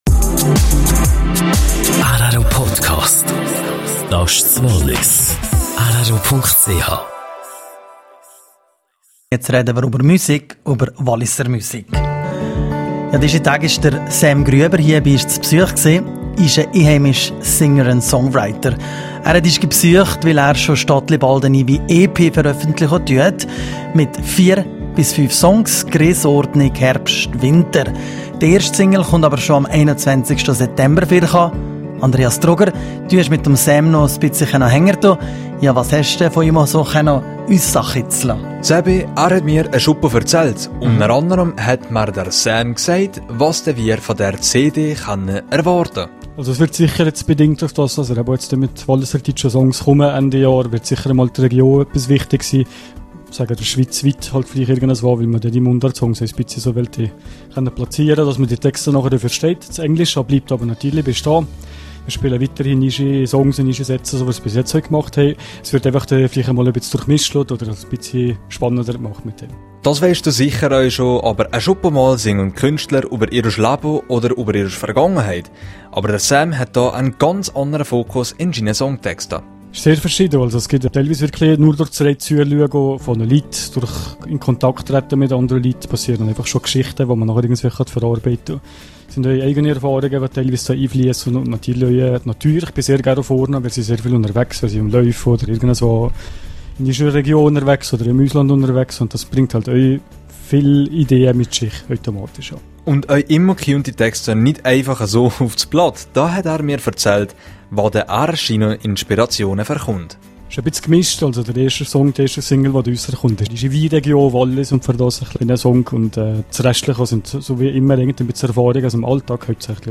Er sang live eines seiner Lieder.